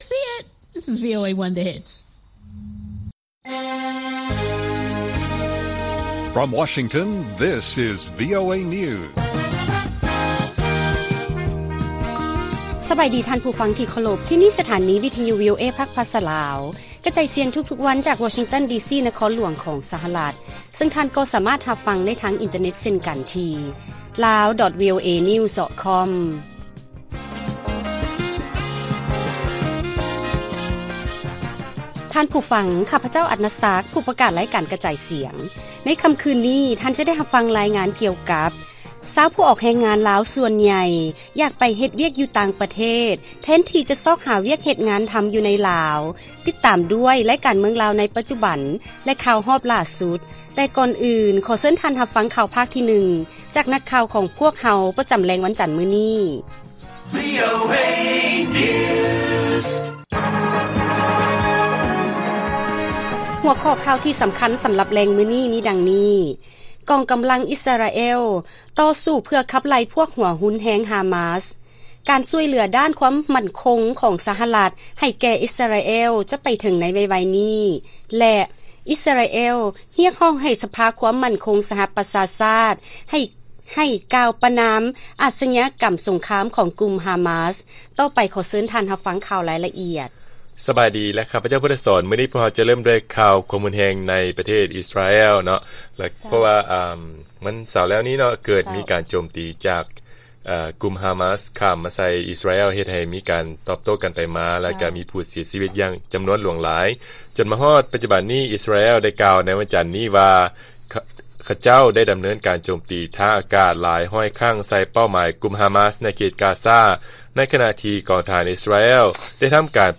ລາຍການກະຈາຍສຽງຂອງວີໂອເອ ລາວ: ກອງກຳລັງ ອິສຣາແອລ ຕໍ່ສູ້ເພື່ອຂັບໄລ່ພວກຫົວຮຸນແຮງ ຮາມາສ